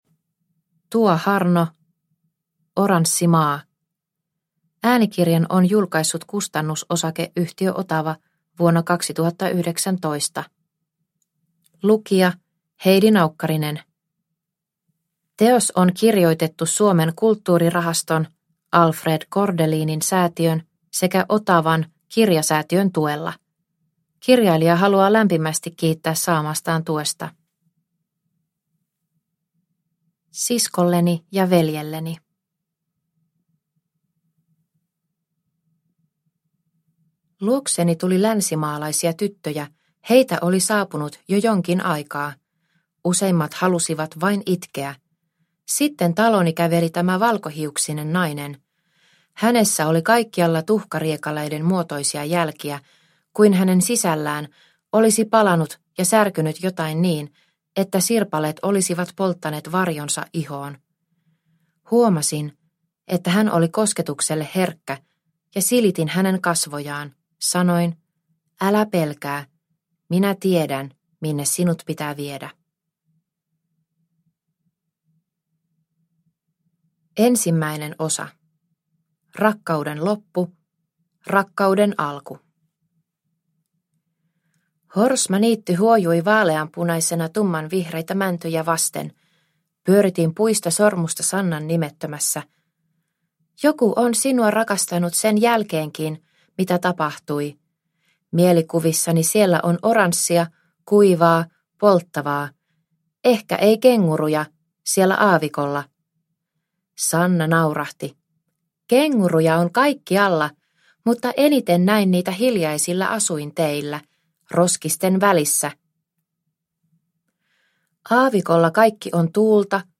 Oranssi maa – Ljudbok – Laddas ner